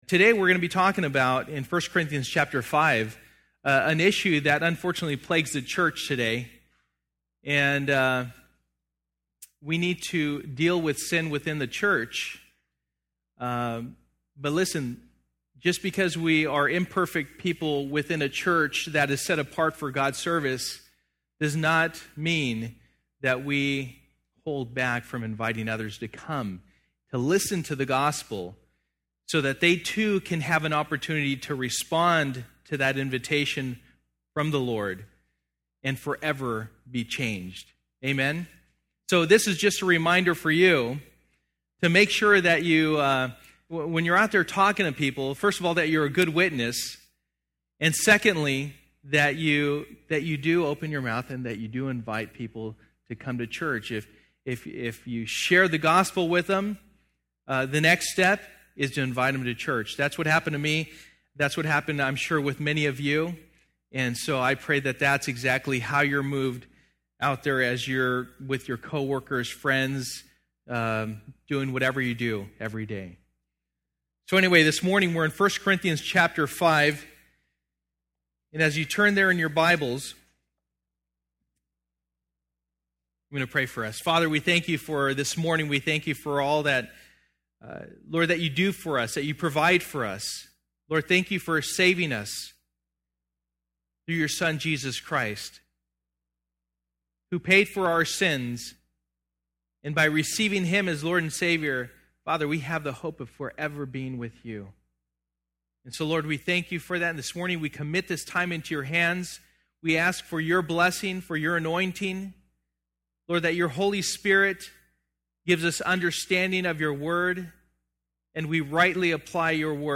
Sold Out Passage: 1 Corinthians 5:1-13 Service: Sunday Morning %todo_render% « Sold Out